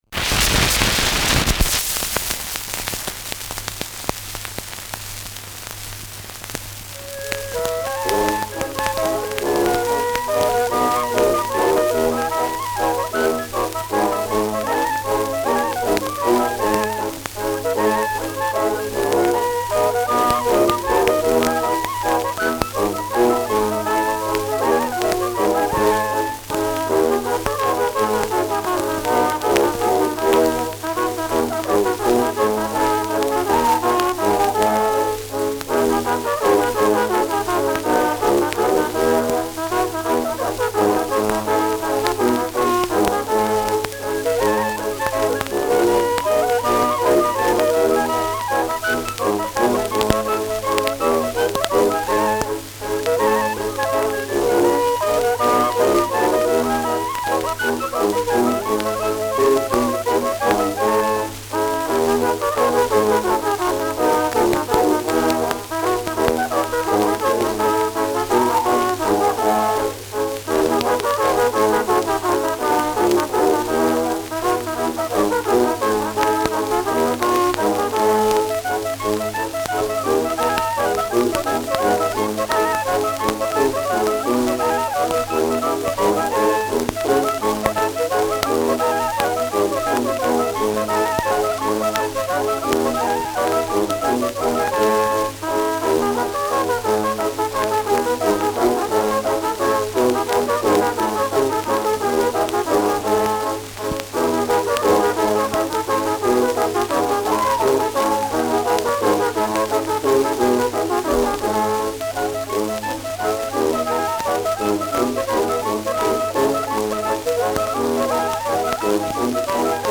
Schellackplatte
Stark abgespielt : Durchgehend leichtes Knacken : Stärkeres Grundrauschen